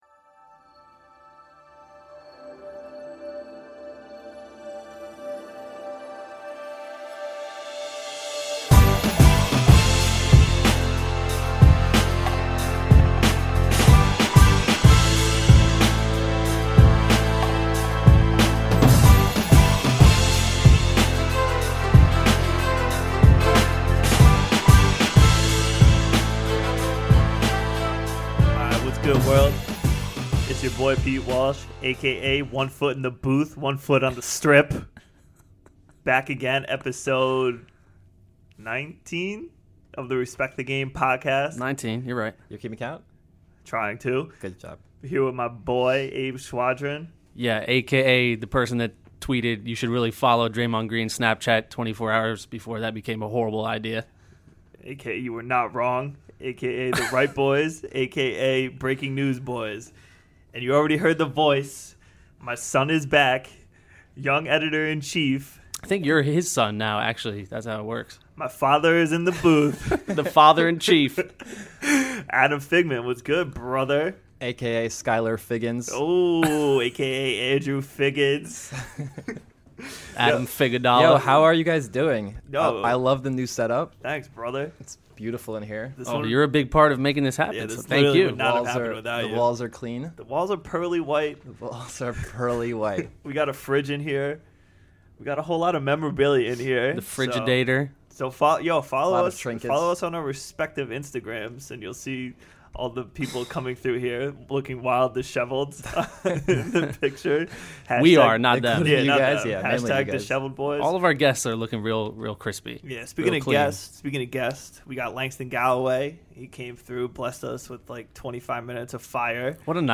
Fresh off his wedding and signing a contract with the New Orleans Pelicans, Langston Galloway drops by the SLAM Studio. Galloway talks about his wedding day, grinding it out in the D-League, what it's like to play on a 10-day contract, being teammates with Kristaps and Melo and what he's looking forward to with the Pelicans.